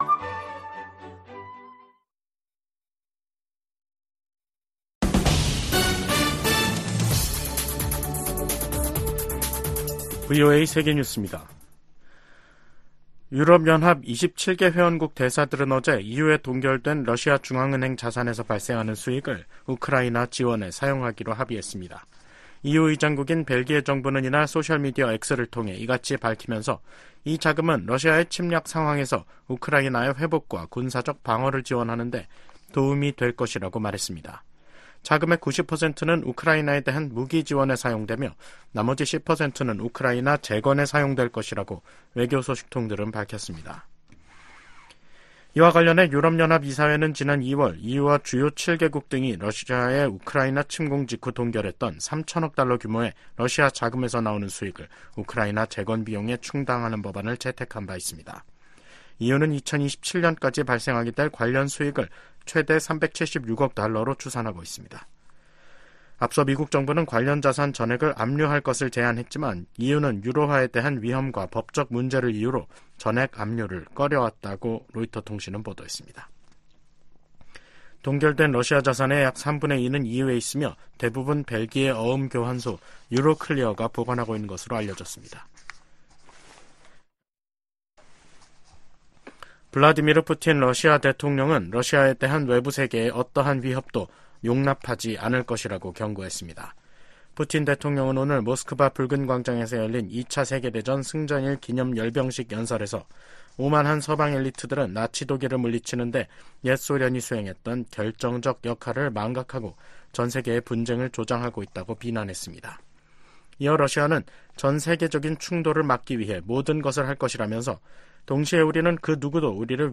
VOA 한국어 간판 뉴스 프로그램 '뉴스 투데이', 2024년 5월 9일 2부 방송입니다. 윤석열 한국 대통령은 취임 2주년 기자회견을 열고 오는 11월 미국 대통령 선거 결과와 관계없이 미한 양국간 동맹관계는 변치 않을 것이라고 말했습니다. 지난 두 달여 동안 북한 남포 유류 항구에 최소 18척의 유조선이 입항한 것으로 나타났습니다. 미 국무부는 북한이 사이버 범죄와 암호화폐 탈취 등 악의적인 사이버 활동을 확대하고 있다고 지적했습니다.